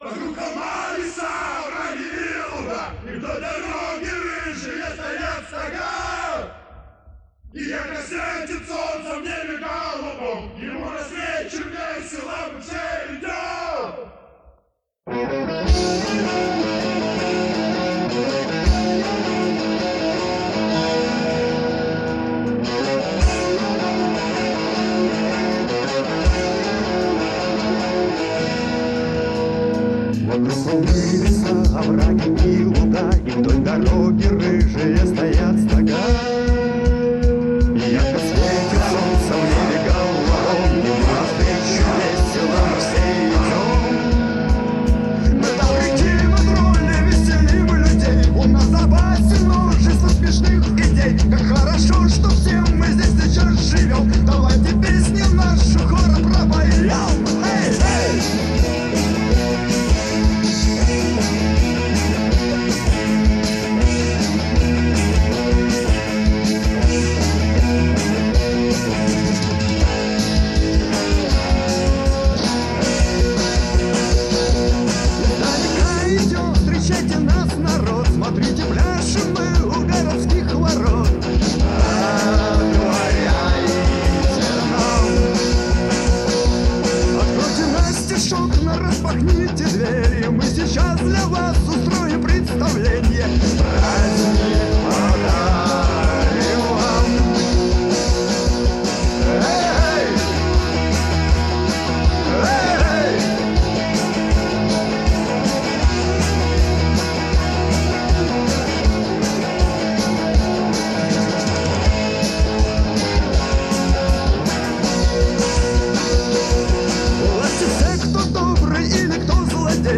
Жанр: Punk